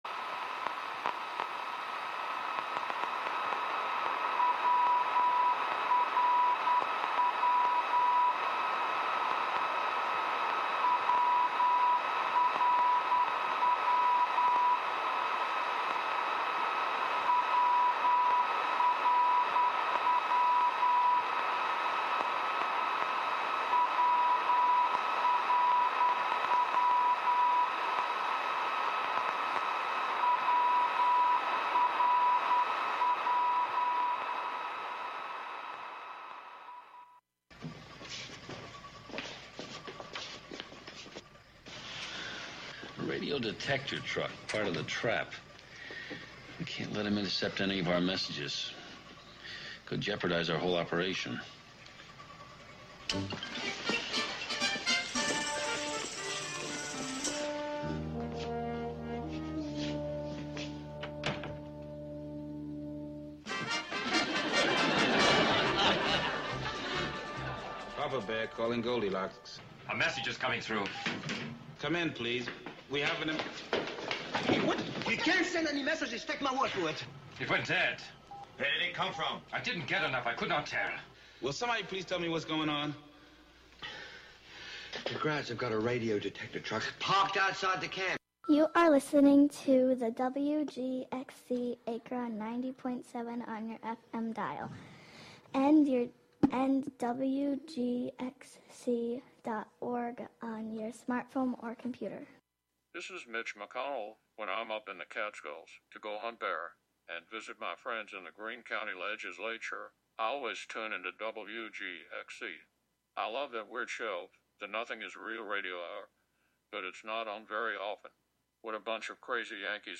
Usually the top ten is recent songs, but sometimes there are theme countdowns.